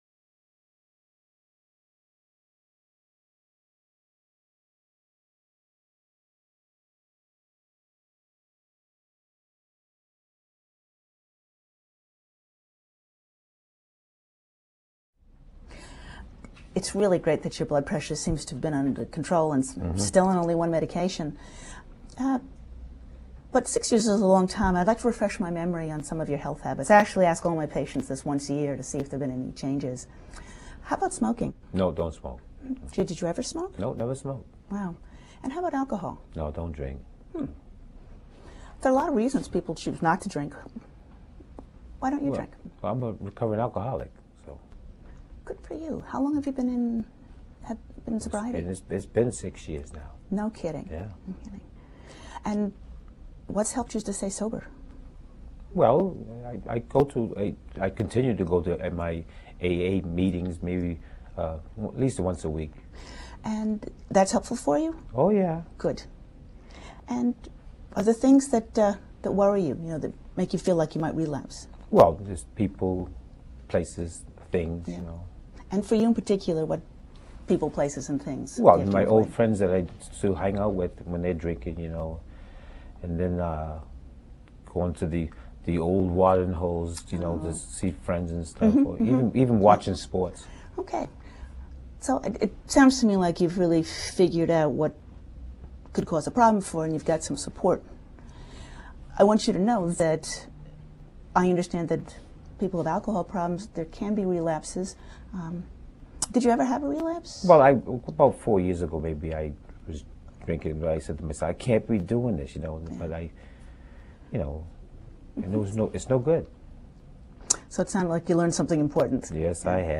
In case four the physician engages in a cross-cultural clinician/patient encounter, which also models the role of the clinician in relapse prevention.